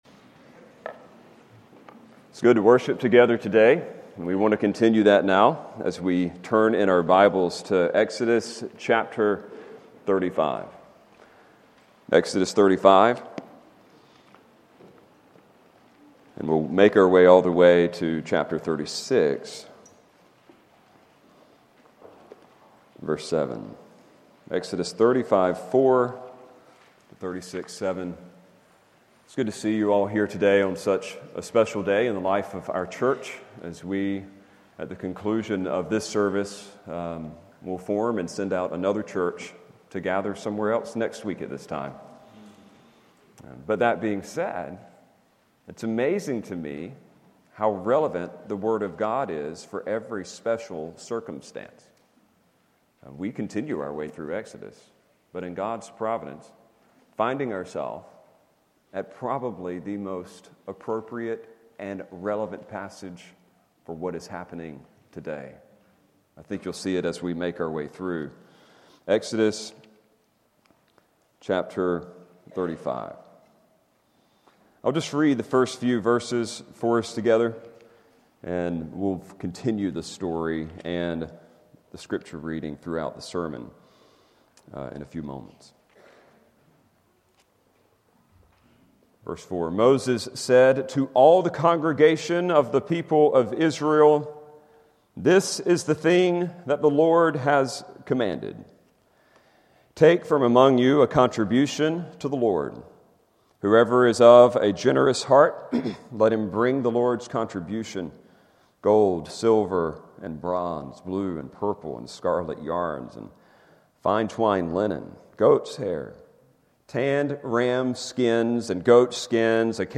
Sermons by Faith Naples